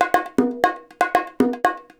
120 BONGOS1.wav